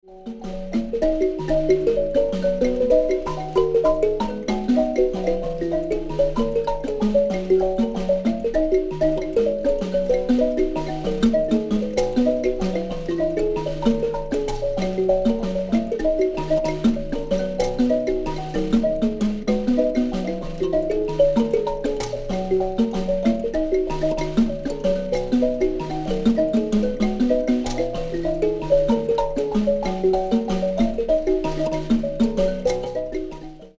B pitch nyamaropa tuning.